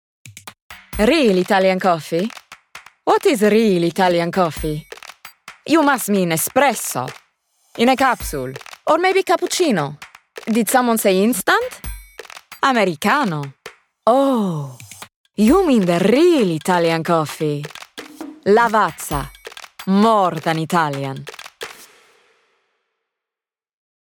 Lavazza - Italian, Cheeky, Sophisticated